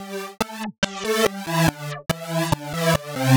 Index of /musicradar/uk-garage-samples/142bpm Lines n Loops/Synths
GA_SacherPad142A-03.wav